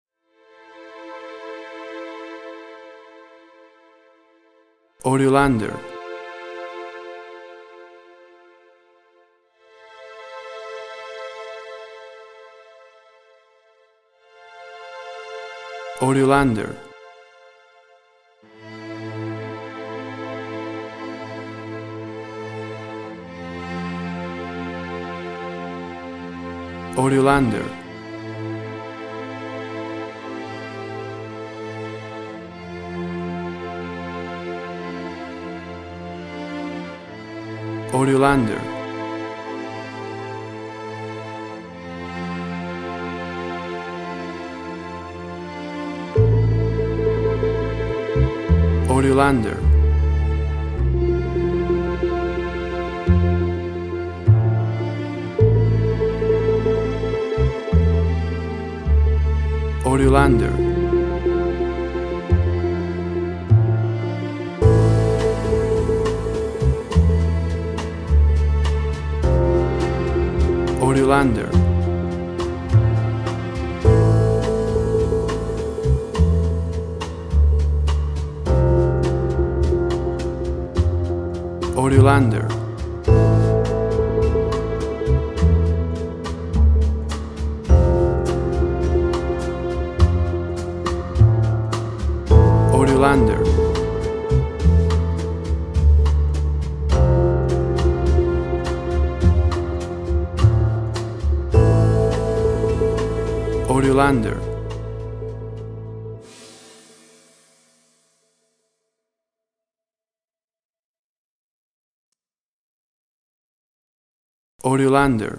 Suspense, Drama, Quirky, Emotional.
Tempo (BPM): 103